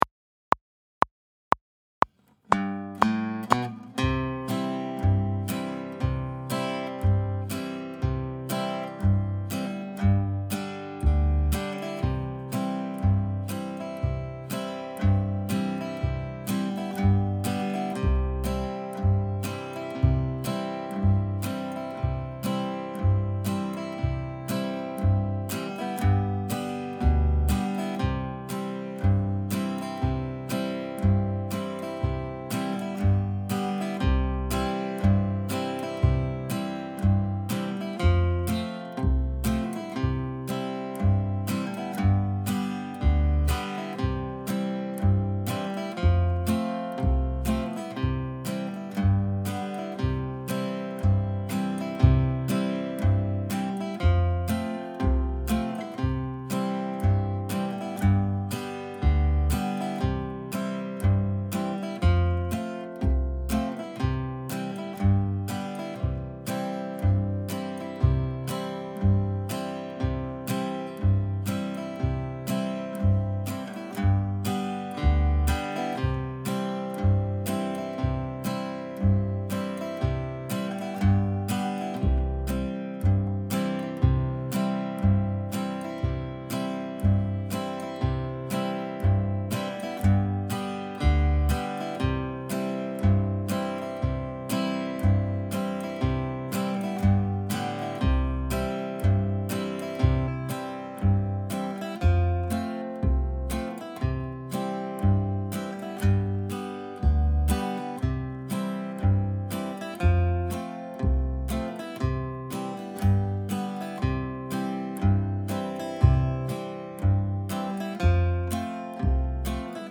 Soldiers Joy Play Along Track – without banjo (C)
Soldiers-Joy-without-Banjo.mp3